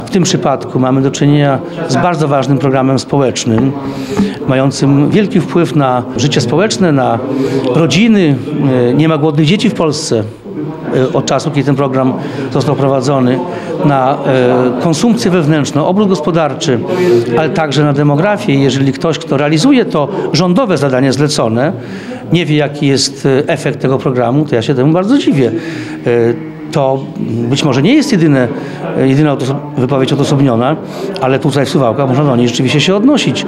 Temat poruszył podczas otwarcia delegatury Urzędu Wojewódzkiego w Suwałkach.